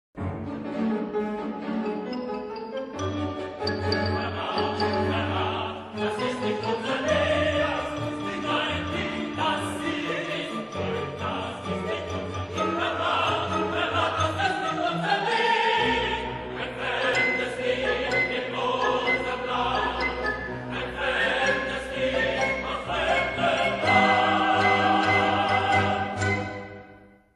Für gemischten Chor und Kammerorchester